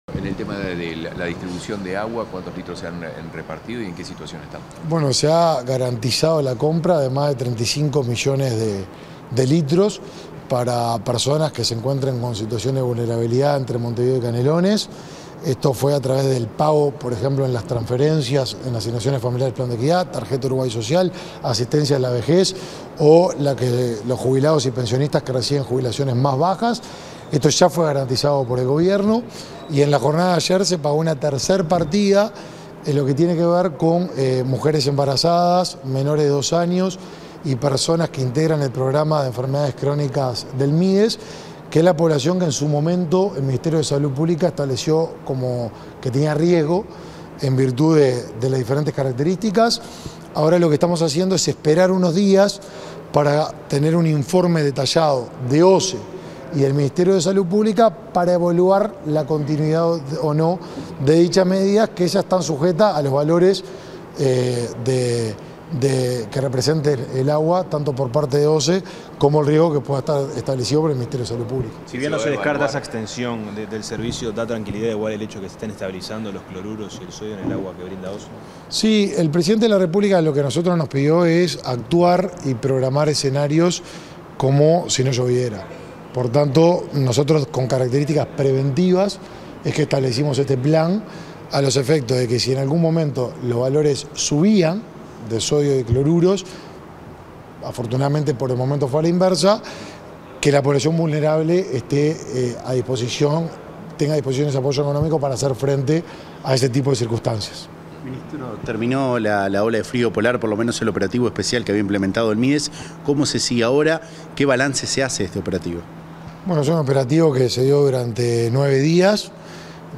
Declaraciones del ministro de Desarrollo Social, Martín Lema
El ministro de Desarrollo Social, Martín Lema, realizó, este 20 de julio, declaraciones a la prensa sobre las medidas por la emergencia hídrica y